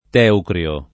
vai all'elenco alfabetico delle voci ingrandisci il carattere 100% rimpicciolisci il carattere stampa invia tramite posta elettronica codividi su Facebook teucrio [ t $ ukr L o ] s. m. (bot.); pl. ‑cri (raro, alla lat., ‑crii )